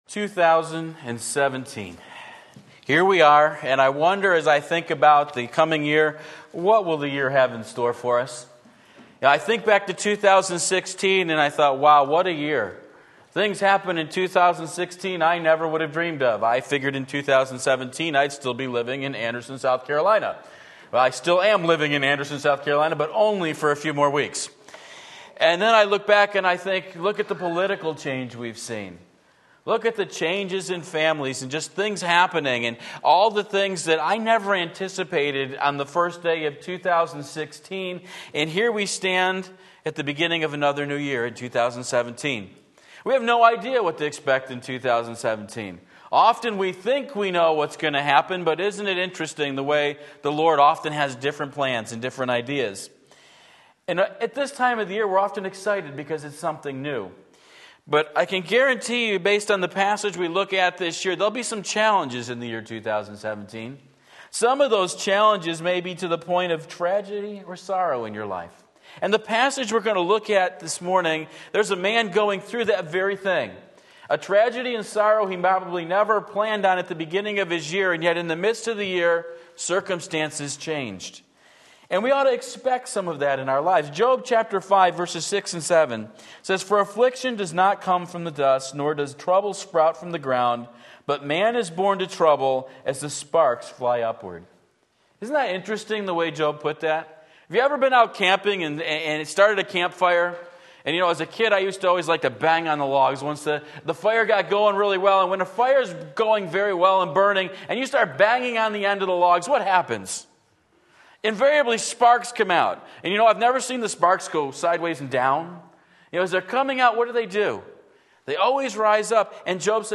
Sermon Link
Faith in Action John 5:45-54 Sunday Morning Service, January 1, 2017 Believe and Live!